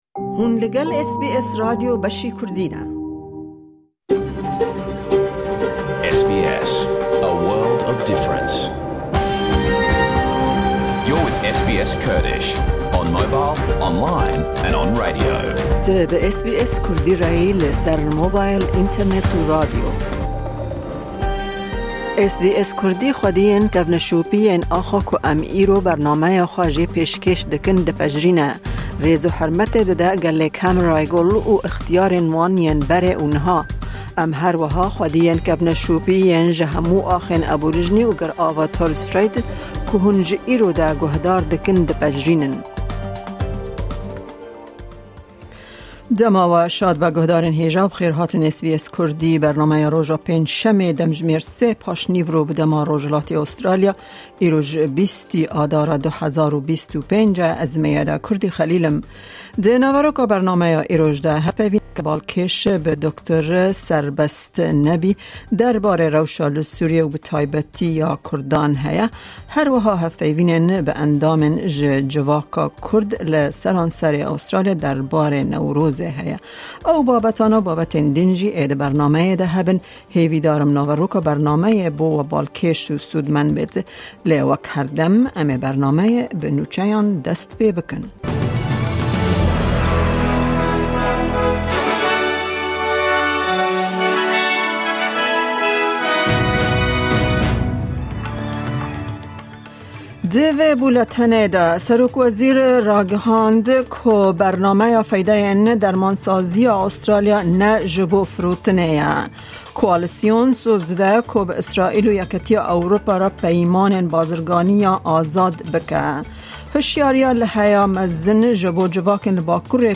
Di vê bernameya taybet de, hevpeyvînên derbarê Newrozê hene, raport û babetên din hene.